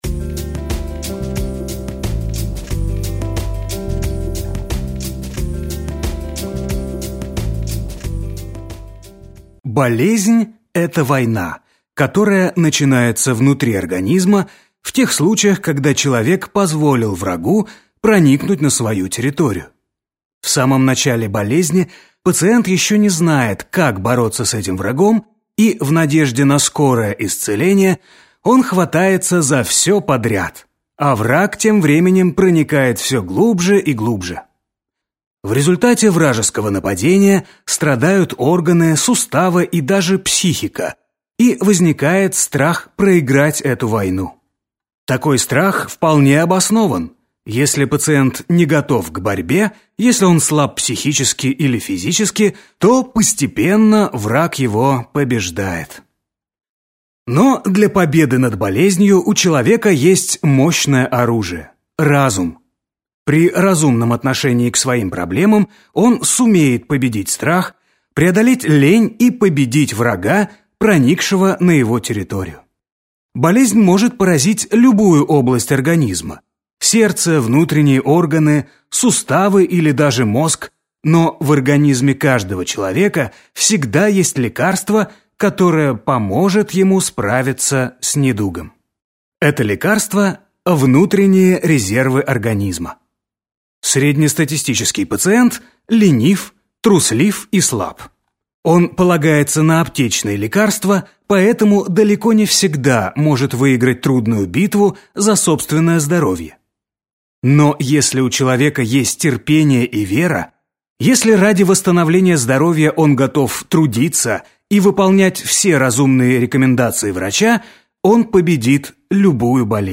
Aудиокнига Перезагрузка.